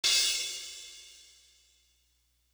Crashes & Cymbals
Guns Is Razors Crash.wav